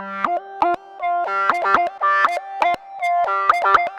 Believe Lead.wav